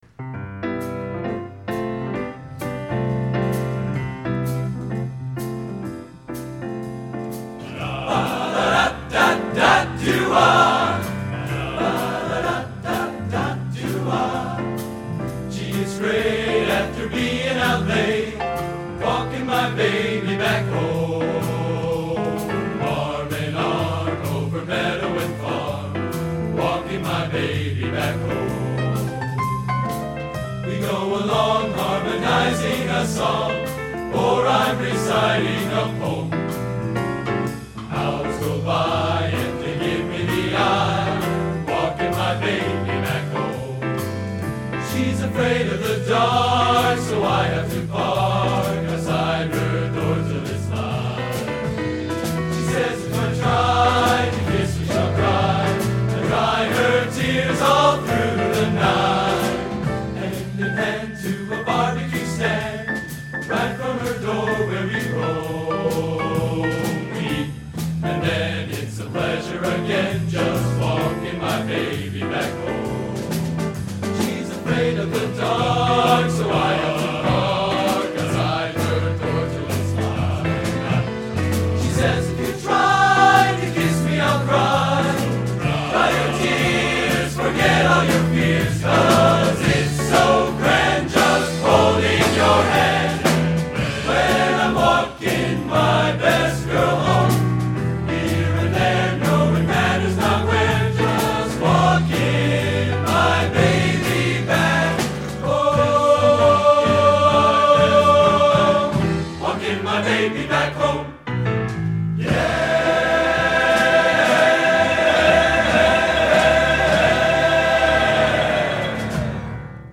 Genre: Popular / Standards | Type: